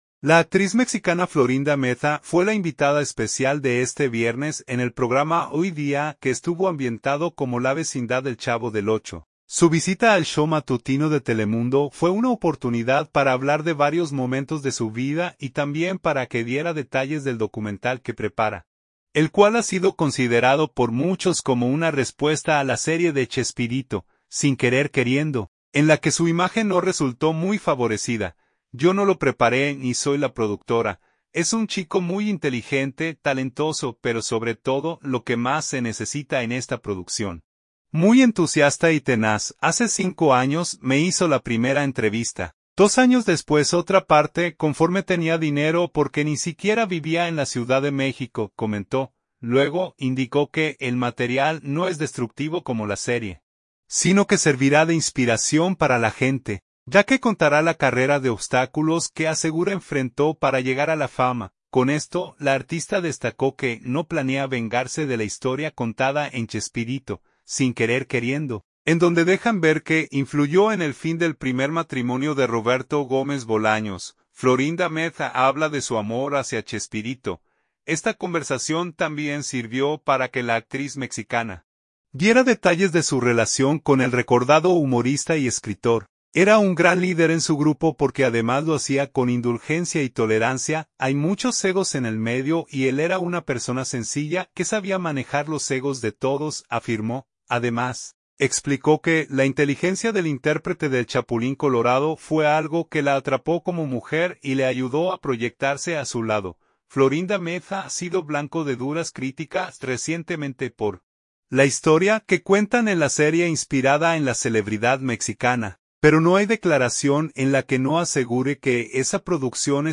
La actriz mexicana Florinda Meza fue la invitada especial de este viernes en el programa Hoy Día, que estuvo ambientado como la vecindad del Chavo del 8.